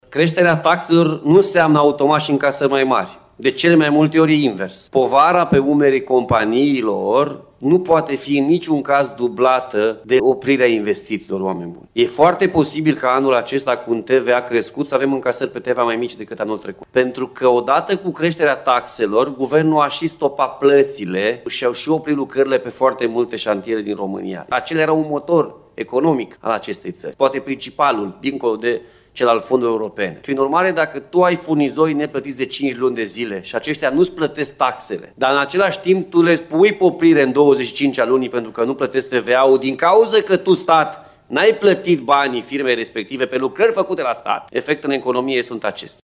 Președintele CJ Timiș, Alfred Simonis, susține că majorarea TVA și creșterea taxelor a dus la o diminuare propriu zisă a fondurilor încasate.